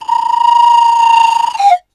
BVIBRA FX1.wav